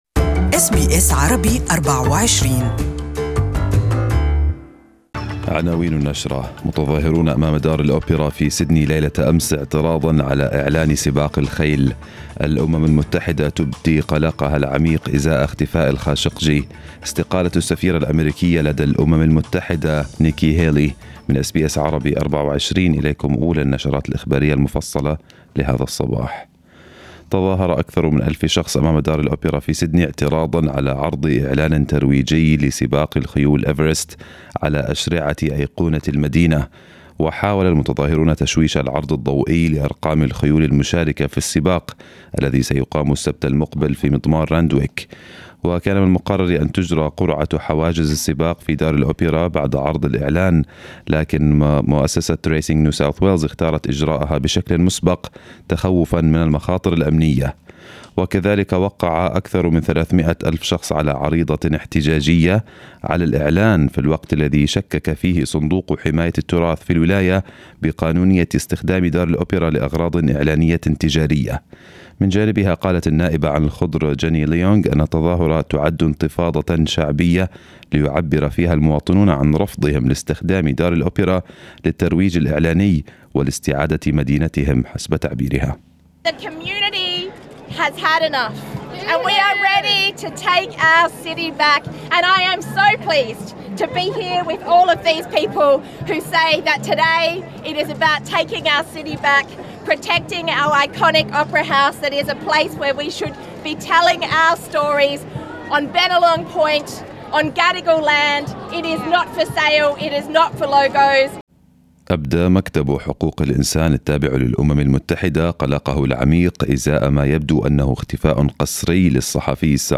Detailed news bulletin for this morning